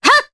Scarlet-Vox_Attack1_Jp.wav